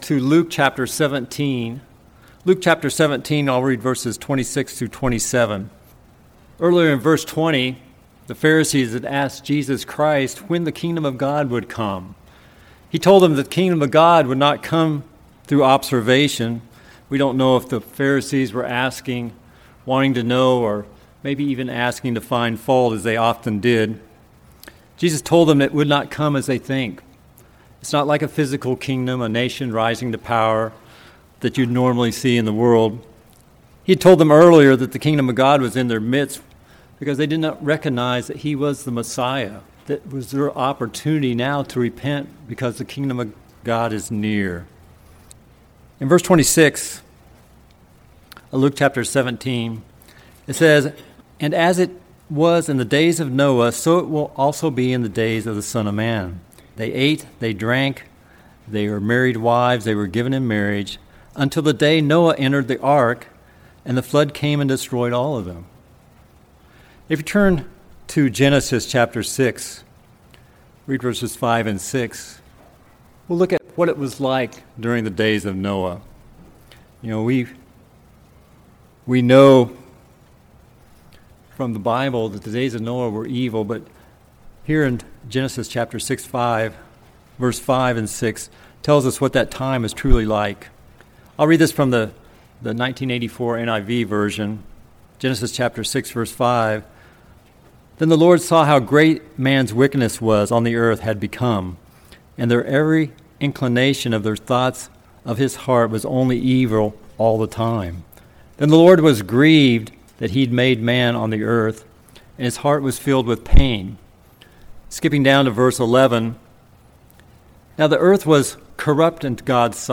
In this sermon we will address six important lessons from Noah we need to apply in our lives.